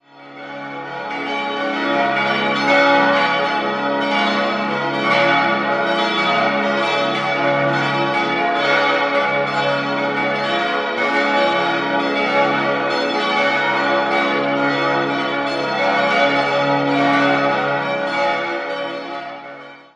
6-stimmiges Geläut: des'-f'-as'-b'-des''-es''